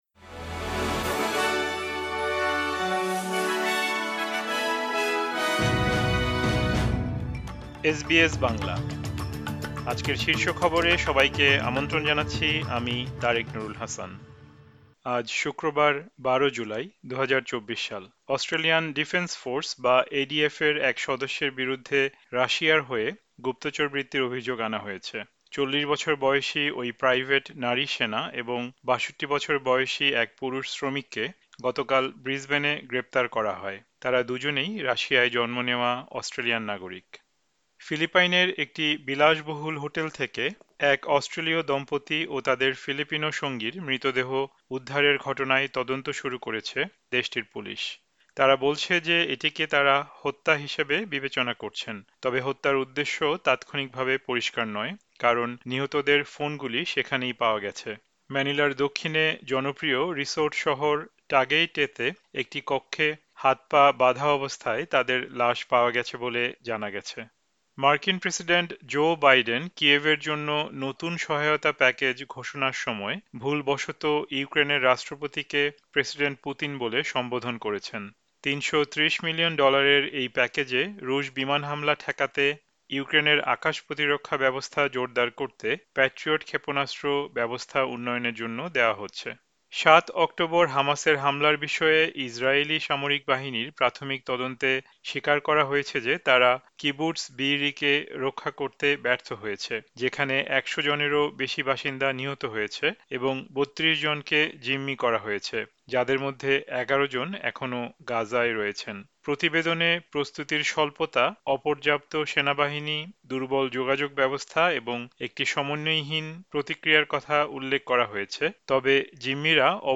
এসবিএস বাংলা শীর্ষ খবর: ১২ জুলাই, ২০২৪